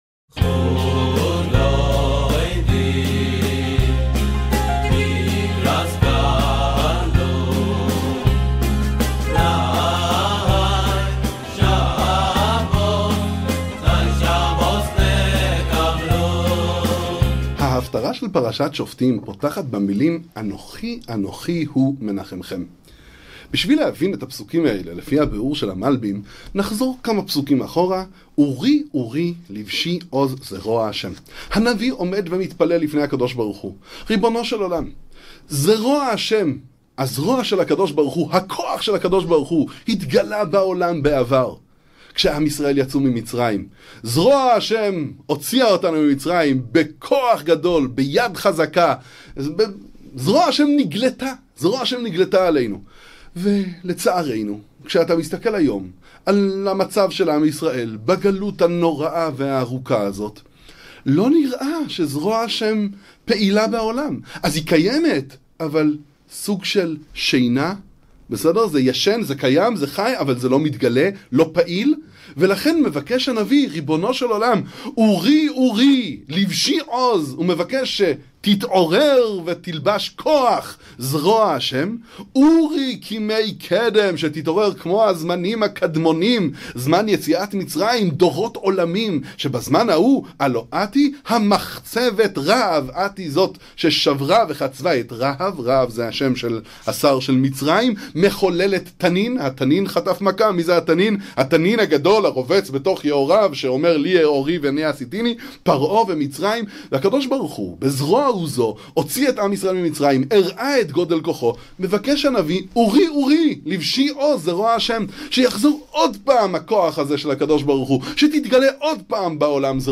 דבר תורה קצר לשולחן שבת